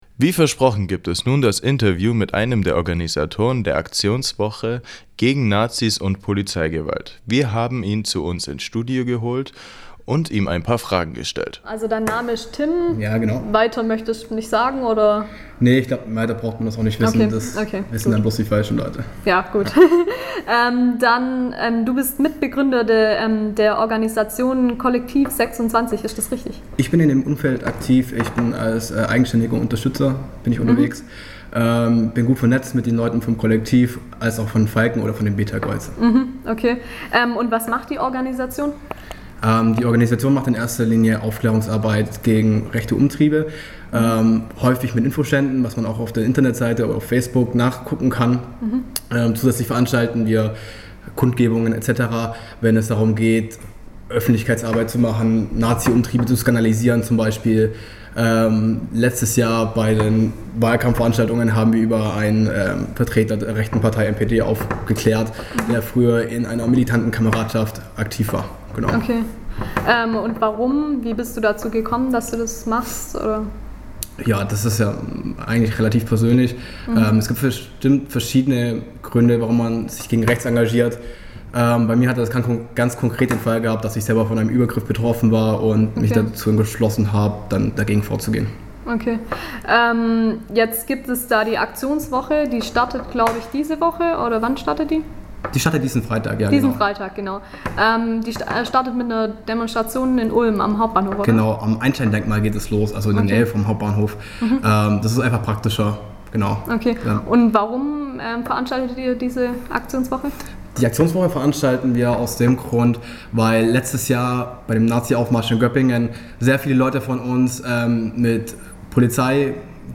Interview mit einem Organisator der Aktionswoche "Gegen Nazis und Polizeigewalt"
interview_aktionswoche_ab.mp3